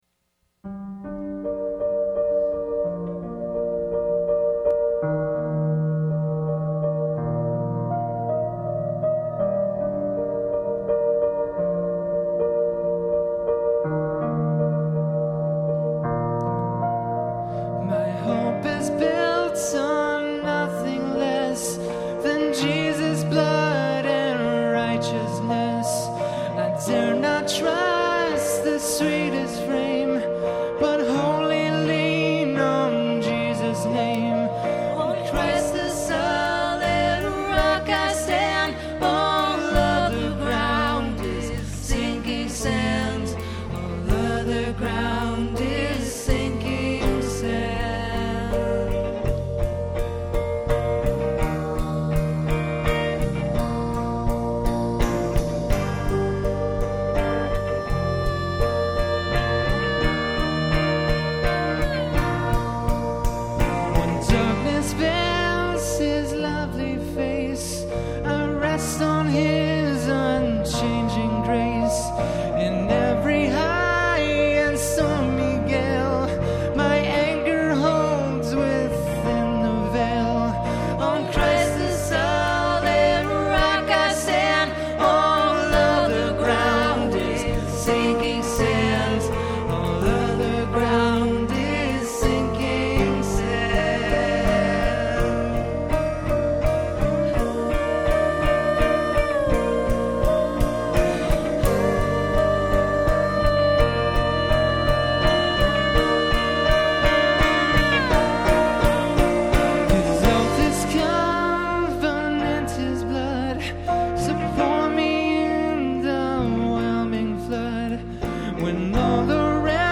Performed live at Terra Nova - Troy on 4/19/09.